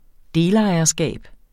Udtale [ ˈdeːlˌɑjʌˌsgæˀb ]